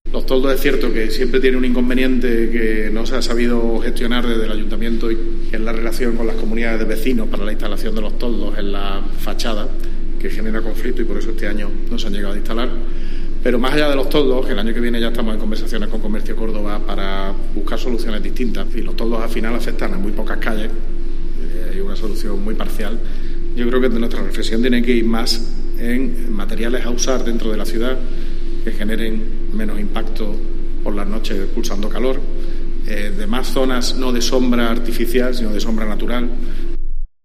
En una rueda de prensa, el regidor ha comentado que "más a largo plazo hay que tomar más medidas de otro calado, que ya no tienen que ver con paliar los efectos de las personas que tengan peores condiciones para poder soportarlo, sino con que en la ciudad se trabaje tratando de paliar efectos en general, como lo relacionado con los toldos" en calles del centro.